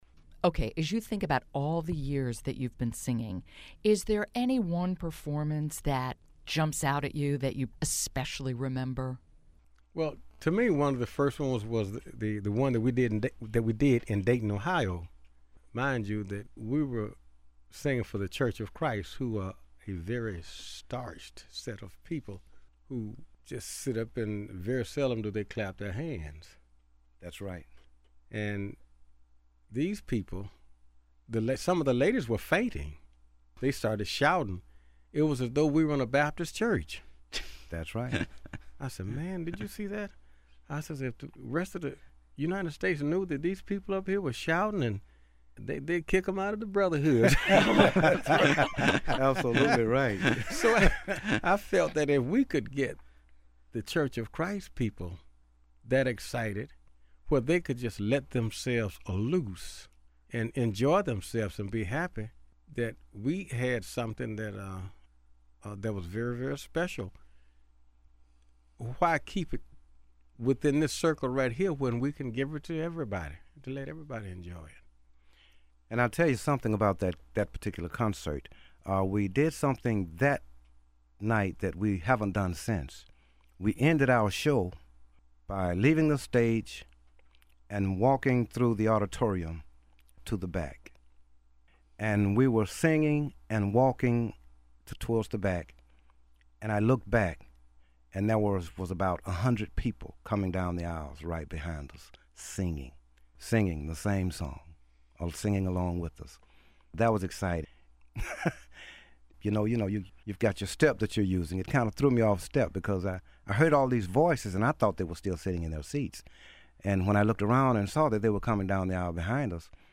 Transcript of interview with members of the Birmingham Sunlights